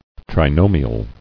[tri·no·mi·al]